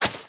Minecraft Version Minecraft Version snapshot Latest Release | Latest Snapshot snapshot / assets / minecraft / sounds / item / shovel / flatten3.ogg Compare With Compare With Latest Release | Latest Snapshot
flatten3.ogg